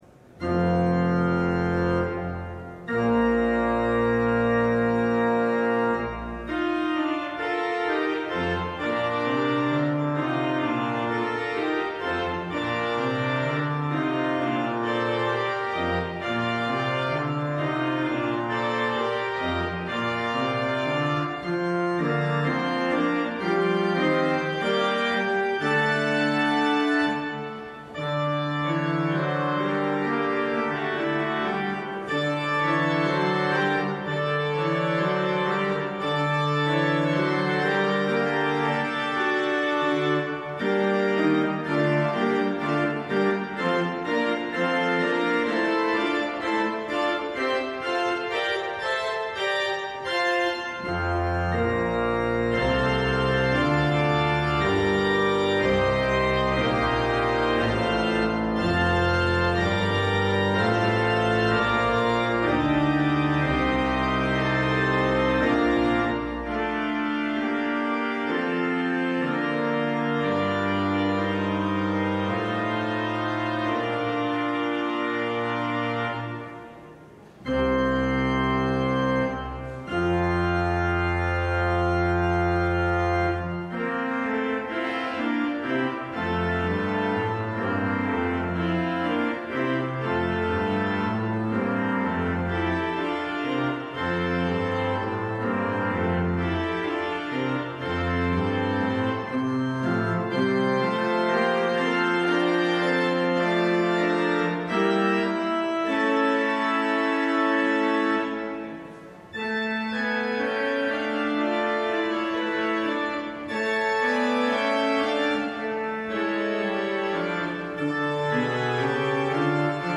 LIVE Morning Worship Service - The Prophets and the Kings: Elijah on Mount Carmel